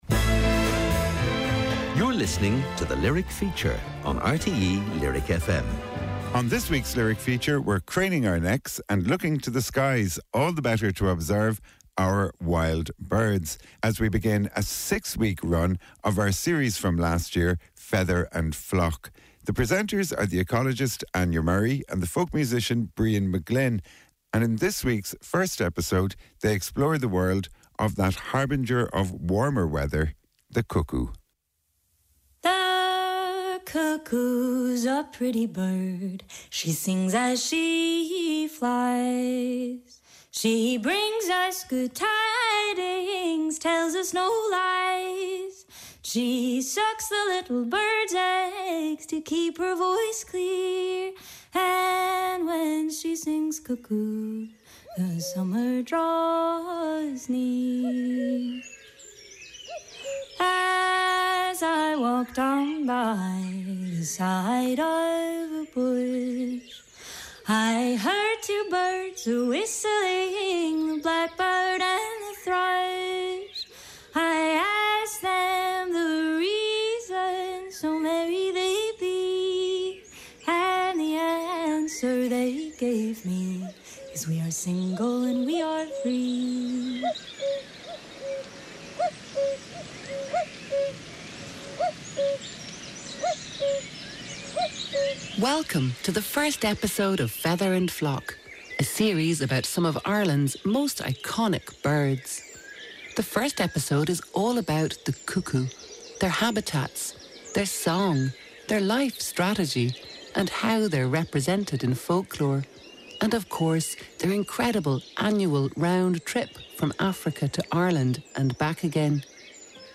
Irish broadcaster RTÉ lyric fm's weekly documentary slot. Programmes about music, literature, visual arts and other areas where creativity is manifest.